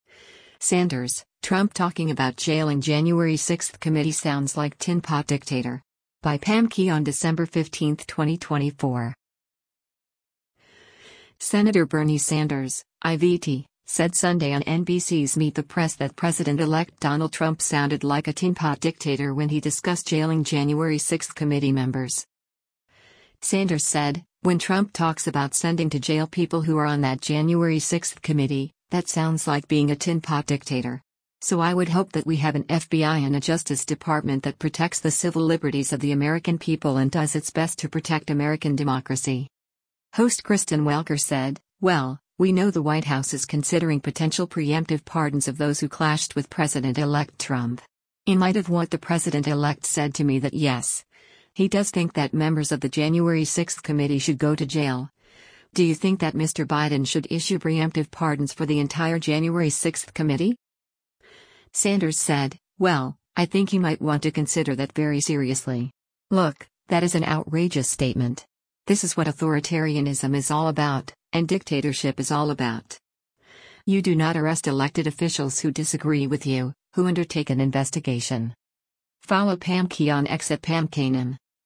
Senator Bernie Sanders (I-VT) said Sunday on NBC’s “Meet the Press” that President-elect Donald Trump sounded like a “tin-pot dictator” when he discussed jailing January 6 committee members.